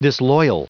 Prononciation du mot disloyal en anglais (fichier audio)
Prononciation du mot : disloyal